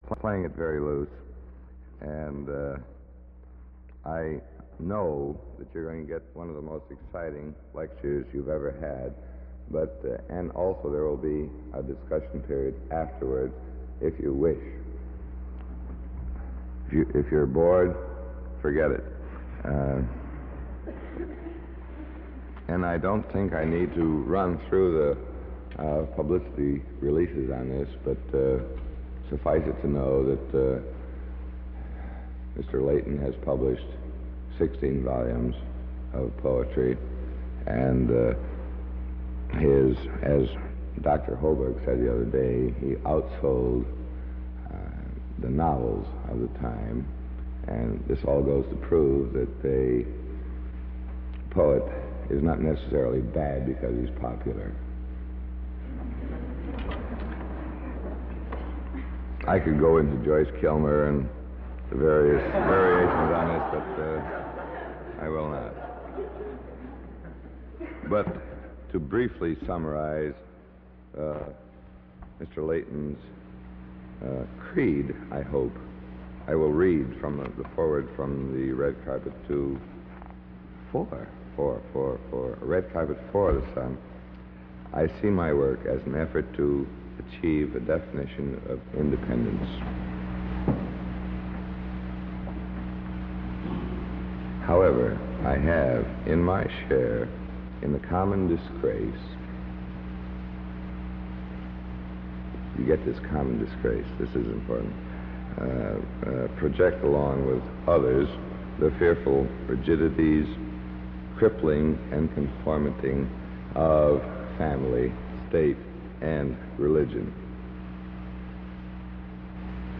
Layton Lecture delivered at the David B. Steinman Festival. St. Lawrence University